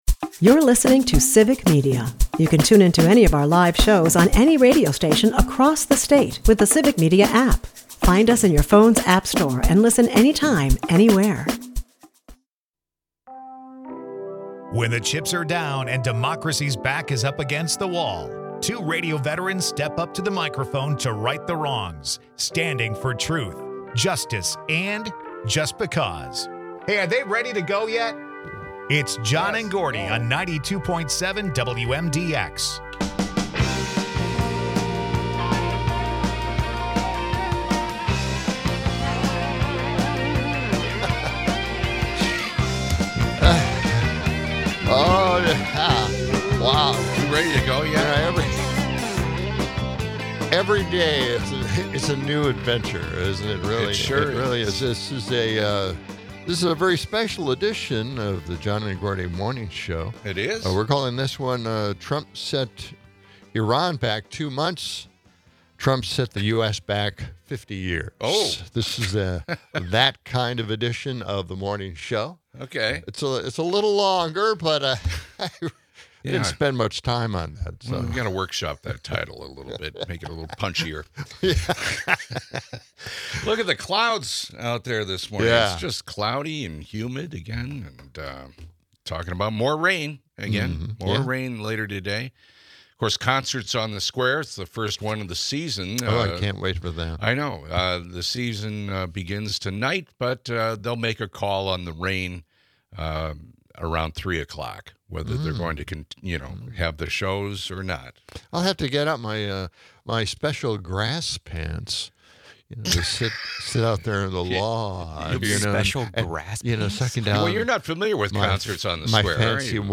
The show wraps with listener calls, touching on socio-economic disparities and the importance of community well-being over individual wealth.